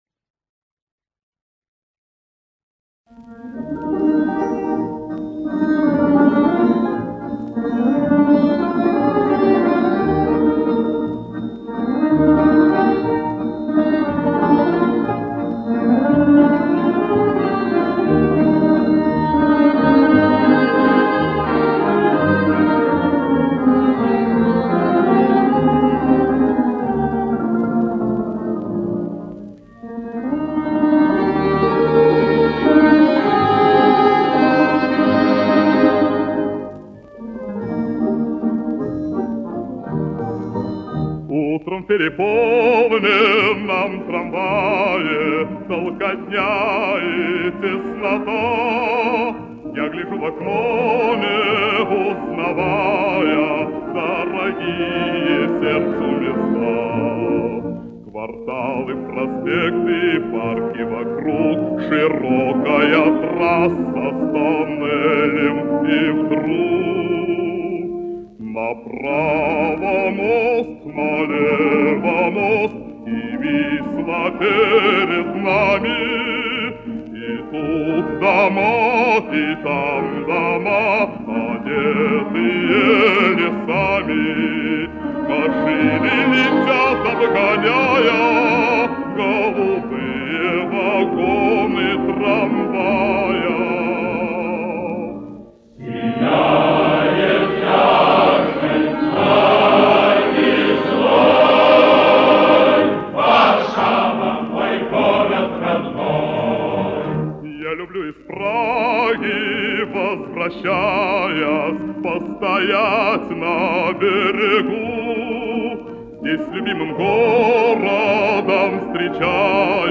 Польская песня